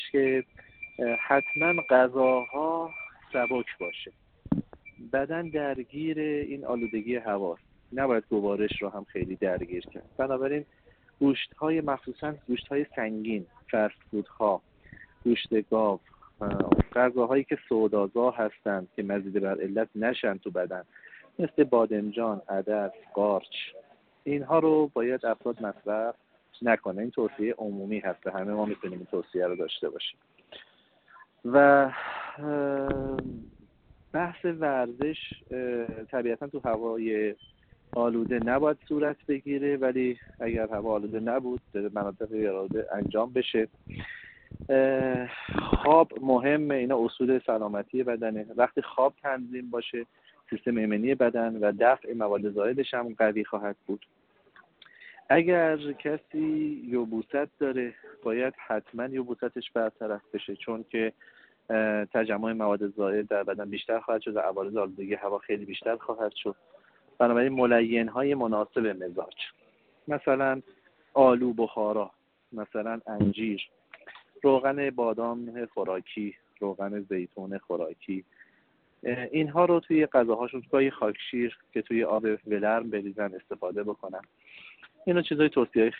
گفت‌وگو با ایکنا